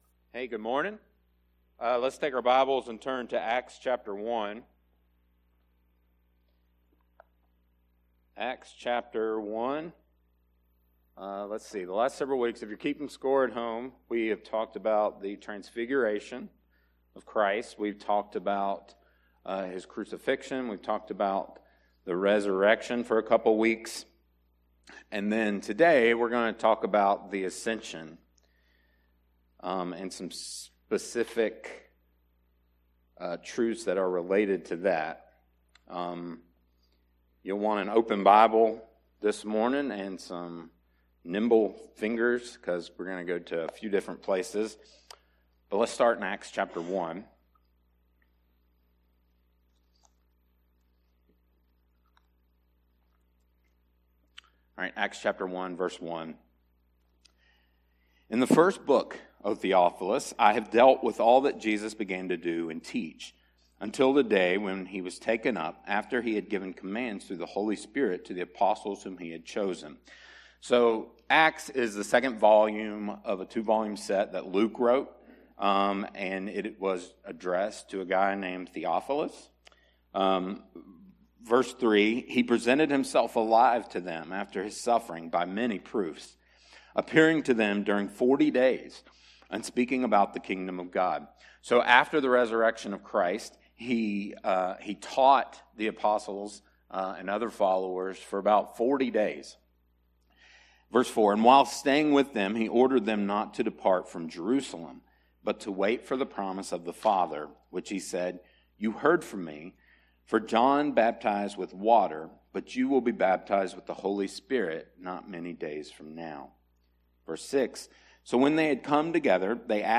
sermon-audio-trimmed-3.mp3